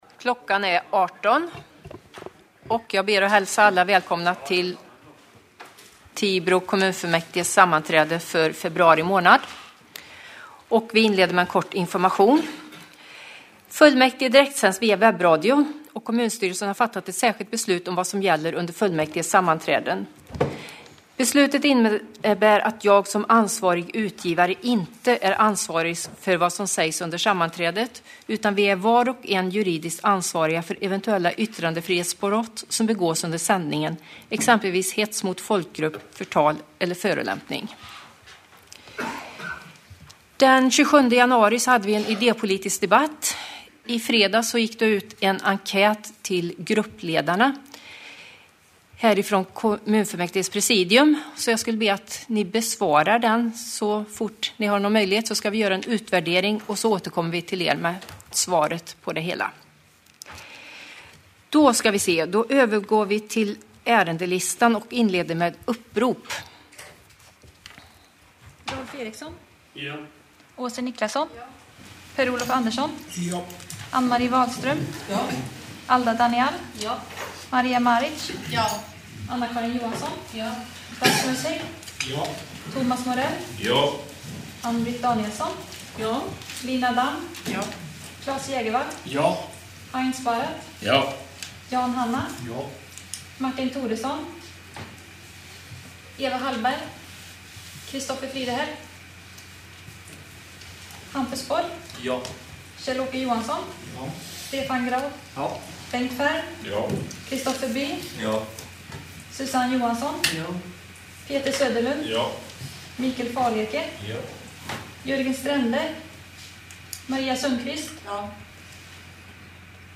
webbsändning från Tibor kommunfullmäktige
Kommunfullmäktige den 24 februari 2020 kl. 18.00.